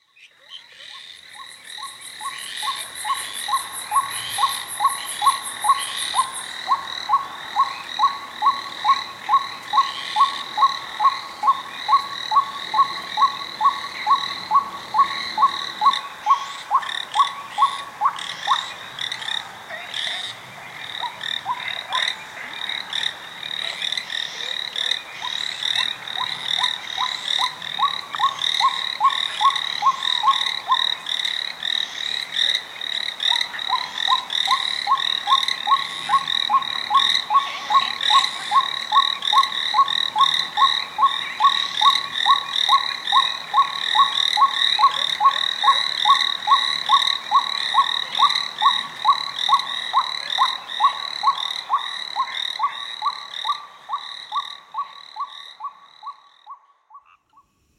佛音 冥想 佛教音乐